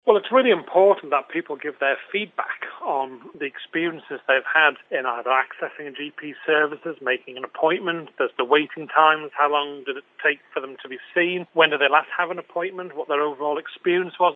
That's the message from the Health and Social Care Minister.
Howard Quayle explains why it's worth the effort: